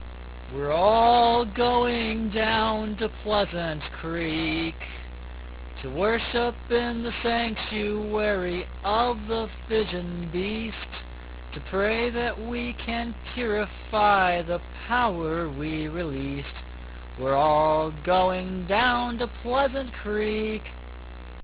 Click this to hear me sing (34K).